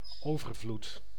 Ääntäminen
IPA: [ɛk.sɛ]